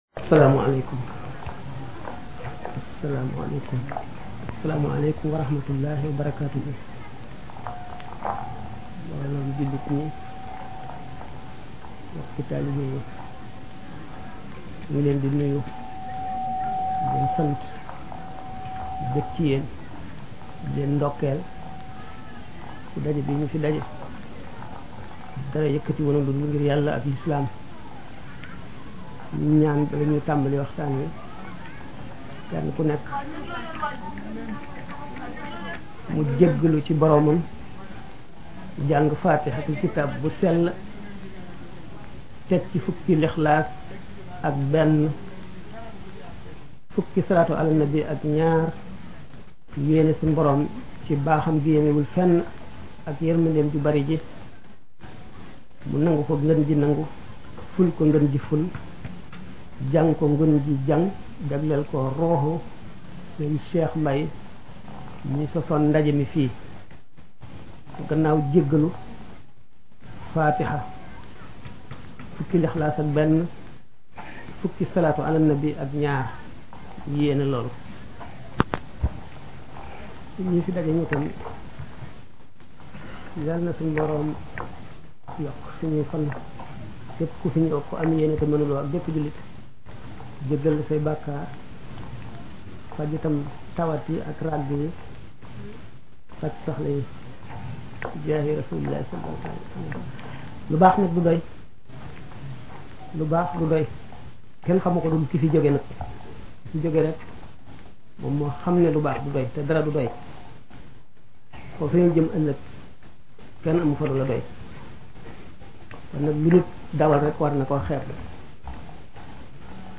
Gamou Belel HIKMA mars 1992